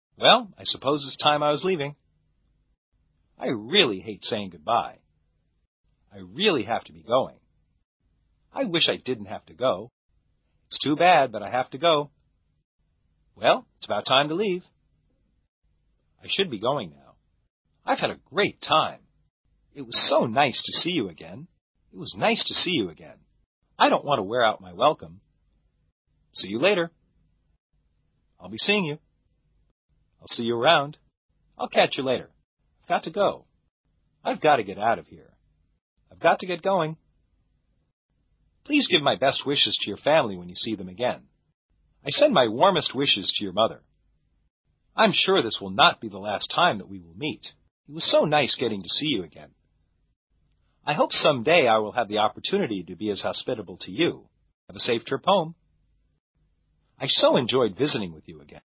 地道美语会话听力口袋丛书：（24）分别